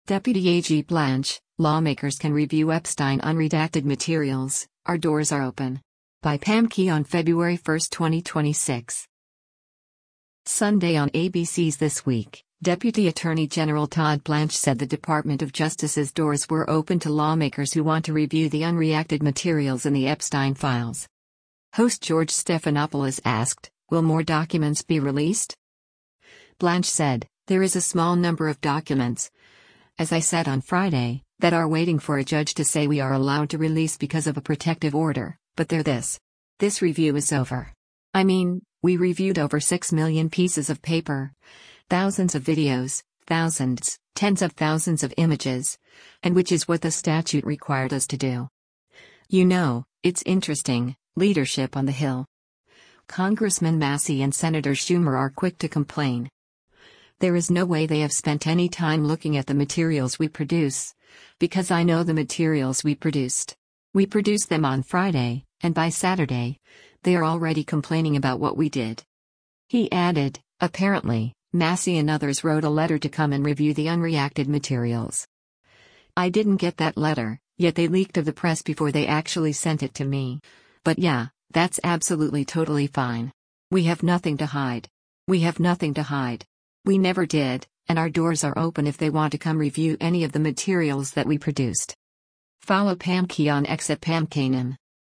Sunday on ABC’s “This Week,” Deputy Attorney General Todd Blanche said the Department of Justice’s doors were open to lawmakers who want to review the “unreacted materials” in the Epstein files.
Host George Stephanopoulos asked, “Will more documents be released?”